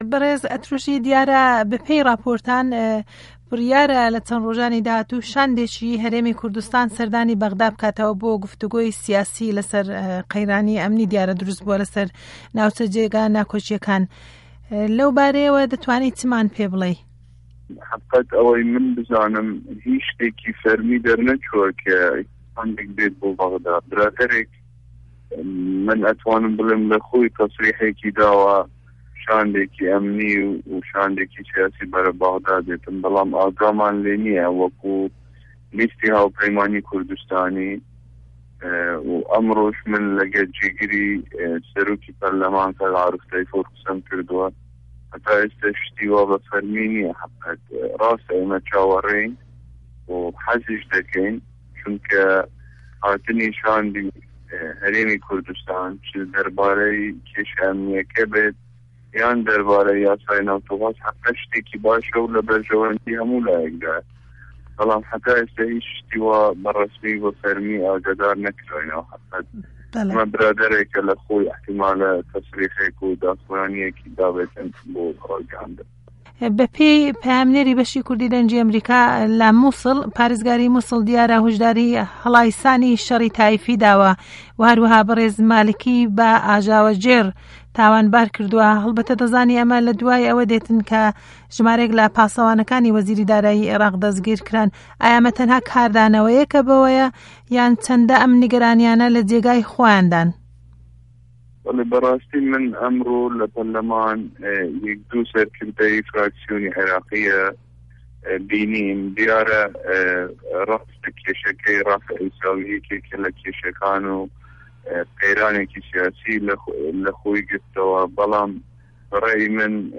وتووێژ له‌گه‌ڵ پارله‌مانتار د. فه‌رهاد ئه‌ترۆشی